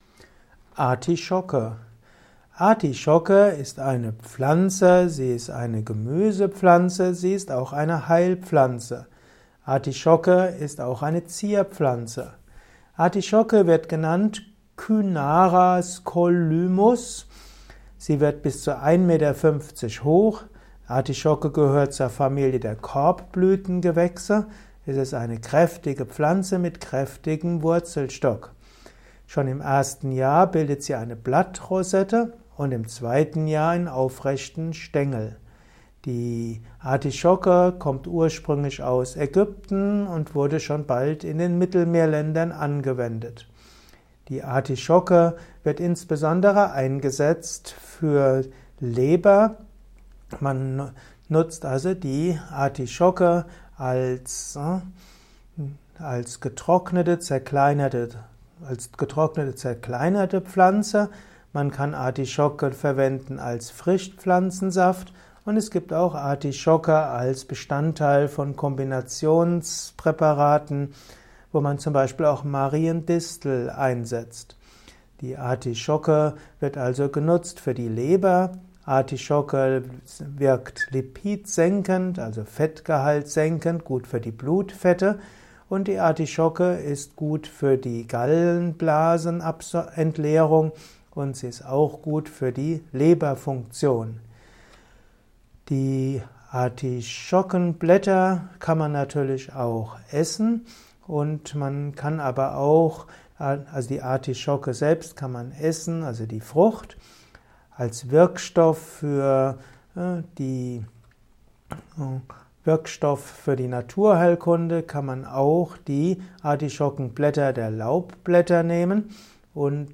Informationen zu Artischocken in diesem Kurzvortrag